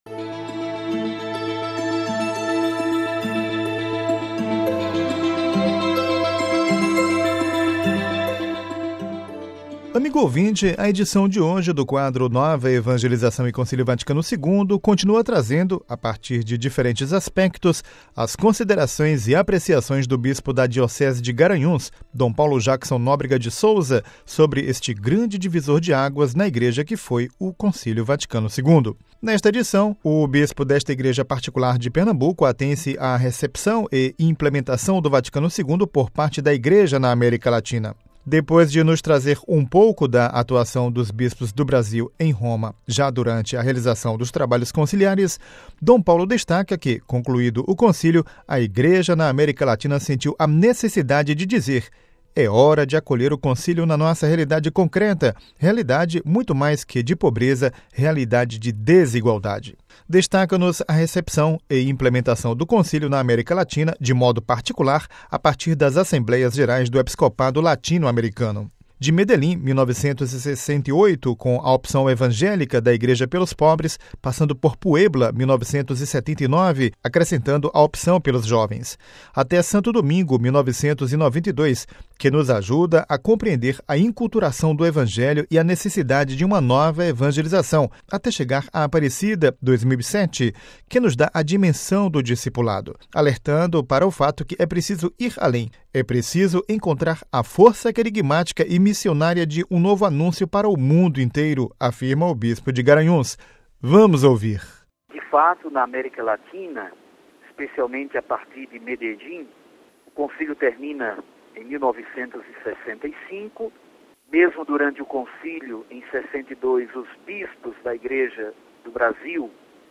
Cidade do Vaticano (RV) - Amigo ouvinte, a edição de hoje do quadro “Nova Evangelização e Concílio Vaticano II” continua trazendo, a partir de diferentes aspectos, as considerações e apreciações do bispo da Diocese de Garanhuns, Dom Paulo Jackson Nóbrega de Souza, sobre este grande divisor de águas na vida da Igreja que foi o Concílio Vaticano II.